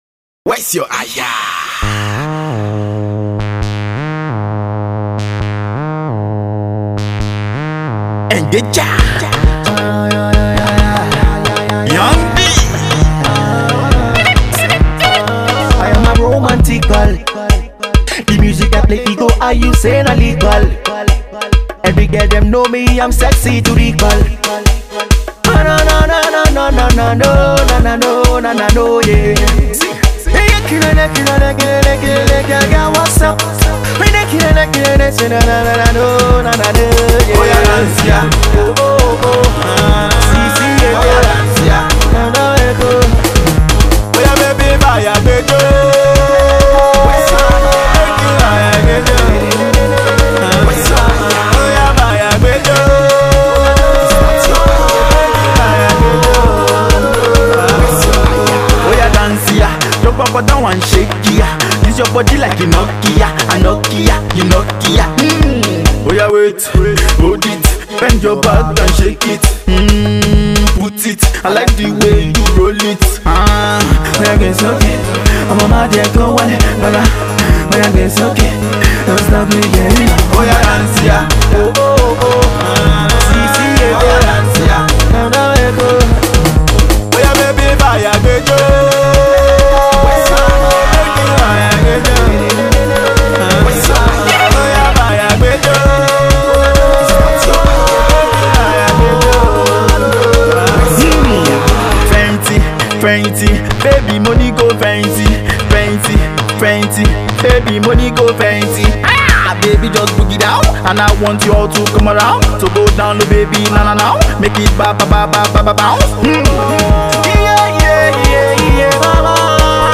is a party starter.